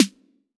6SNARE 2.wav